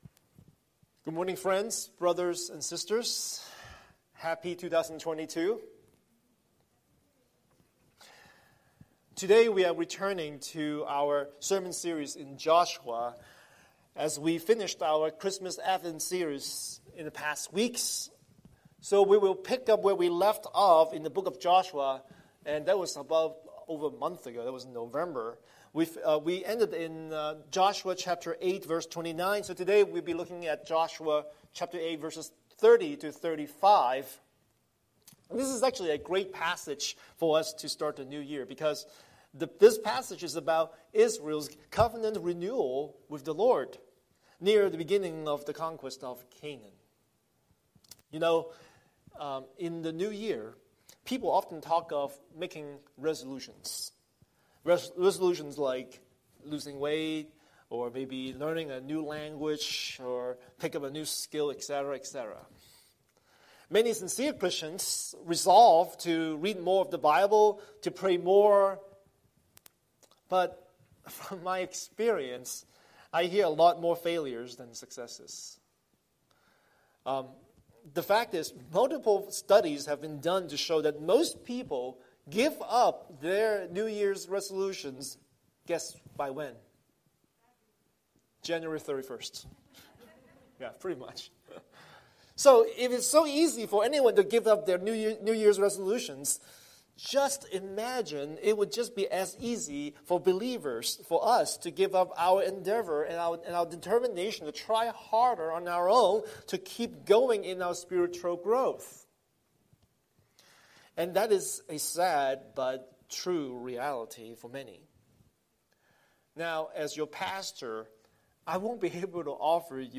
Scripture: Joshua 8:30–35 Series: Sunday Sermon